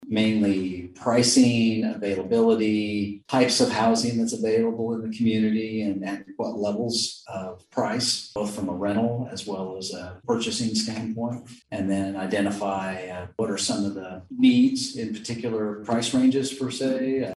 The discussion came up during Manhattan city manager Ron Fehr’s monthly update to the Pott County Commission.